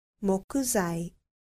• もくざい
• mokuzai